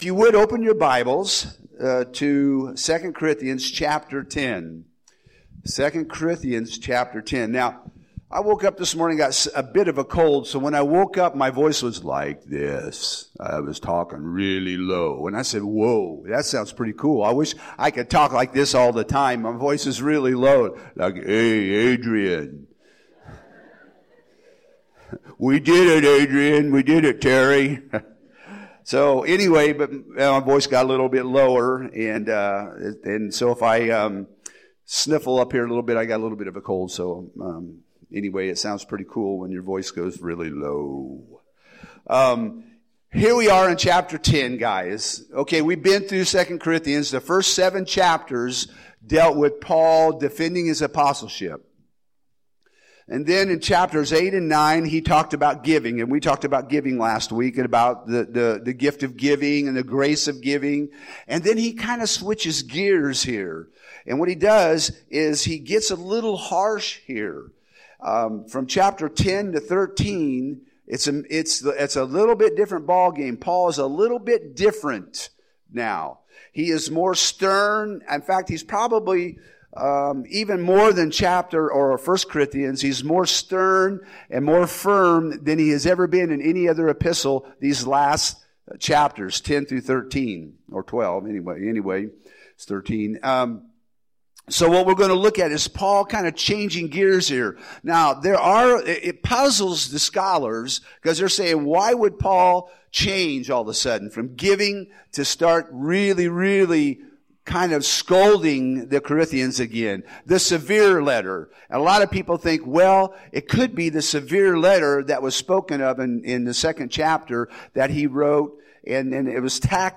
Home › Sermons › 2 Corinthians 10